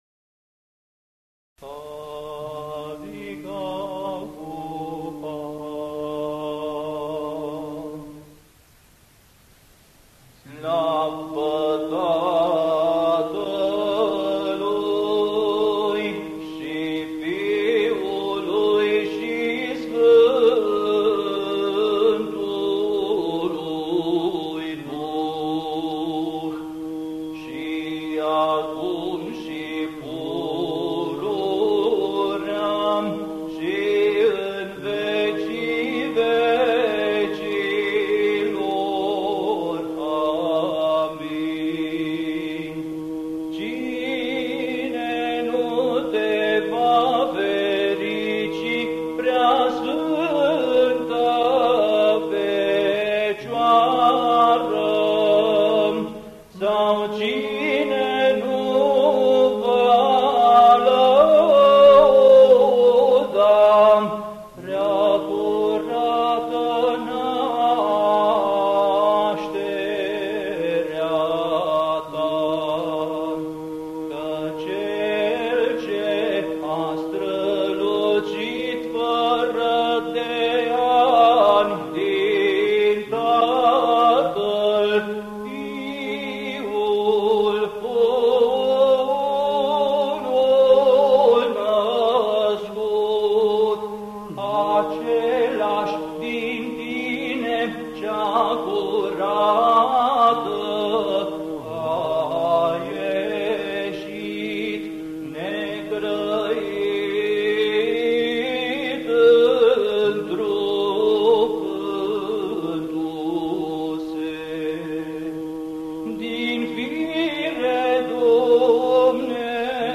Index of /INVATAMANT/Facultate Teologie pastorala/Muzică bisericească și ritual/Dogmaticile
06. Dogmatica glasul 6.mp3